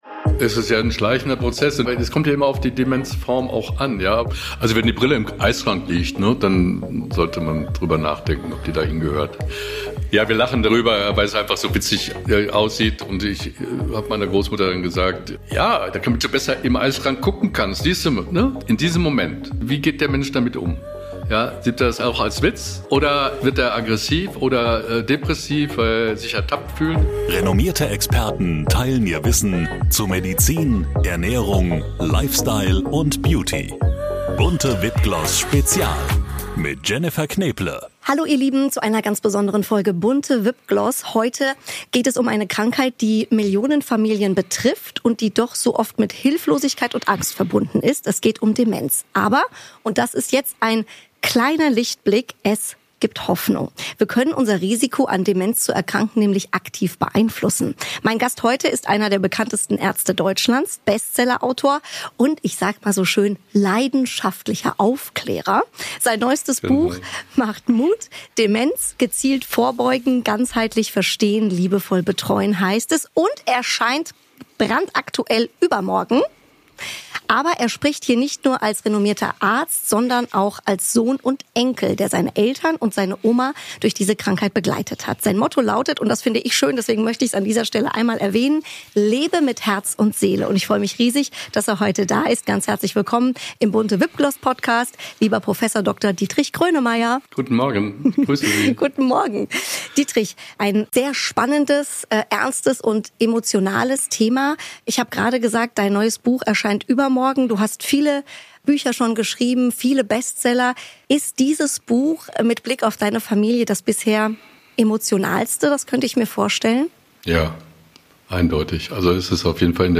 -- In dieser Folge BUNTE VIP GLOSS spricht Podcast-Host Jennifer Knäble mit Prof. Grönemeyer über die vielschichtigen Aspekte von Demenz. Er erklärt, dass die Krankheit durch die Blockierung von Nervenleitungen im Gehirn verursacht wird – insbesondere durch Beta-Amyloid- und Tau-Proteine. Traumatische Erfahrungen wie Kriegserlebnisse können die Symptome zusätzlich verstärken, weshalb psychologische Unterstützung entscheidend ist.